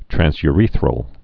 (trănsy-rēthrəl, trănz-)